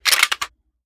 7Mag Bolt Action Rifle - Slide Forward-Down 002.wav